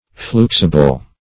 Search Result for " fluxible" : The Collaborative International Dictionary of English v.0.48: Fluxible \Flux"i*ble\, a. [Cf.LL. fluxibilis fluid, OF. fluxible.] Capable of being melted or fused, as a mineral.